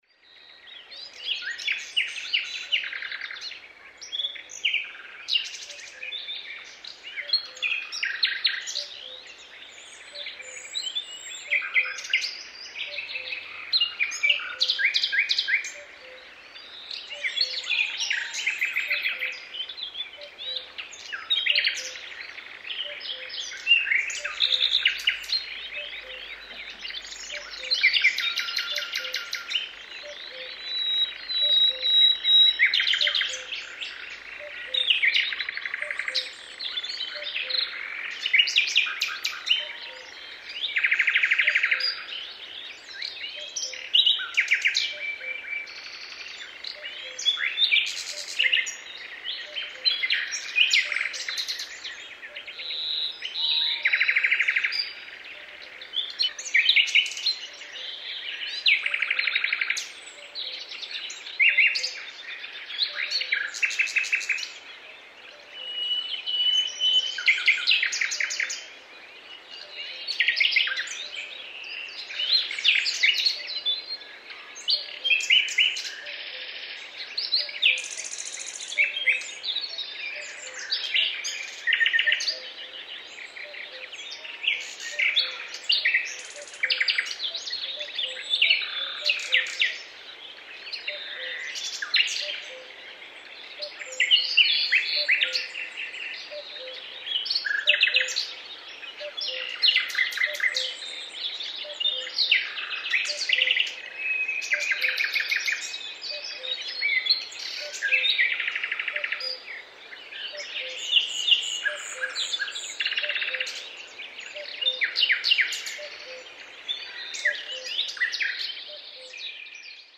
Звук русского леса в стиле французского